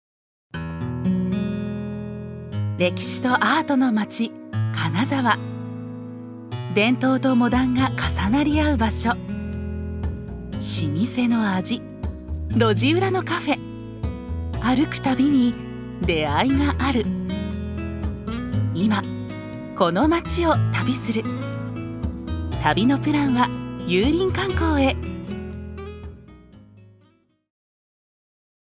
ナレーション４